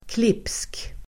Uttal: [klip:sk]